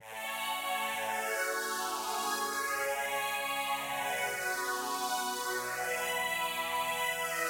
描述：EDM的合成器 发送链接。
Tag: 170 bpm EDM Loops Synth Loops 975.87 KB wav Key : Unknown Logic Pro